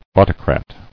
[au·to·crat]